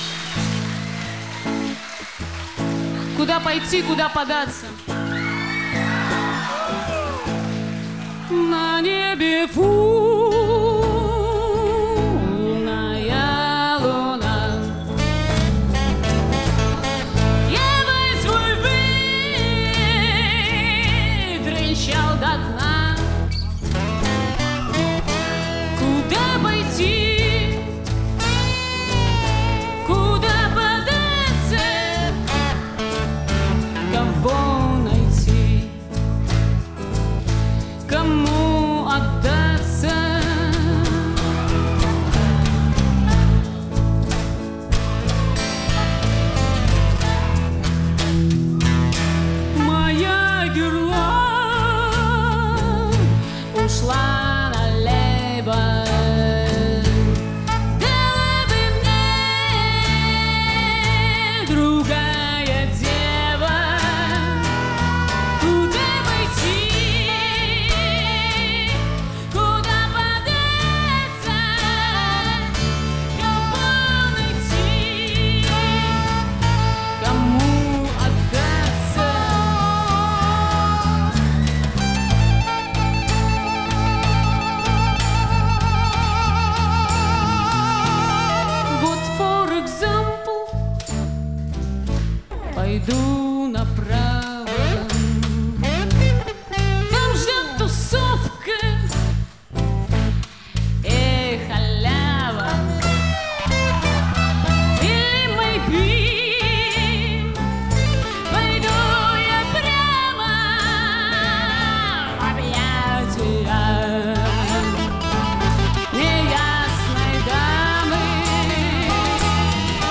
Концертный диск, стиль — акустика.
бас, мандолина, голос
перкуссия
саксофон, флейта
гитара